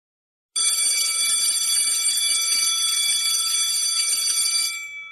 Bell Ringing Efecto de Sonido Descargar
Bell Ringing Botón de Sonido